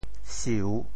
« 宿 » quel est le mot en Teochew ?
siu3.mp3